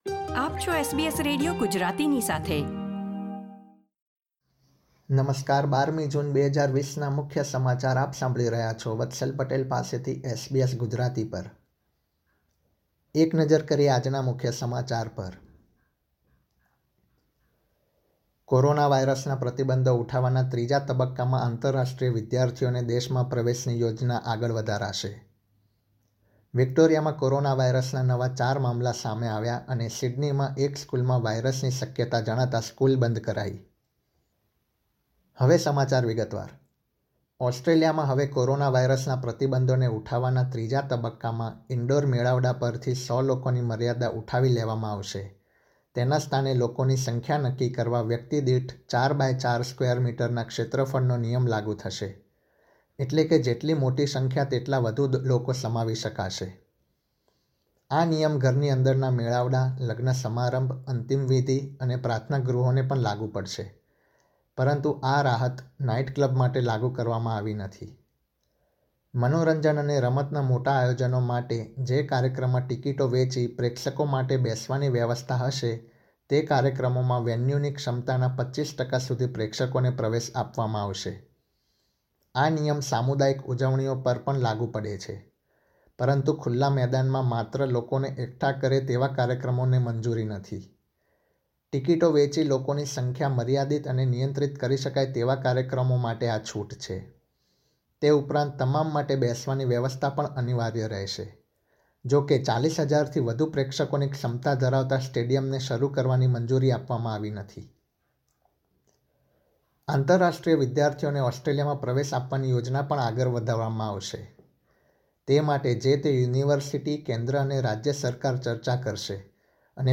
SBS Gujarati News Bulletin 12 June 2020